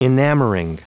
Prononciation du mot enamoring en anglais (fichier audio)
Prononciation du mot : enamoring
enamoring.wav